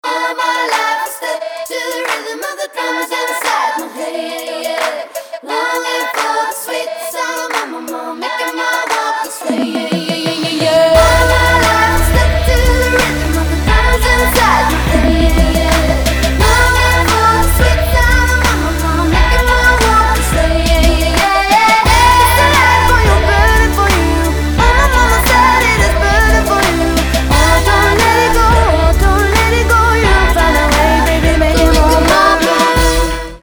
женский вокал